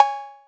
808 Cowbell.WAV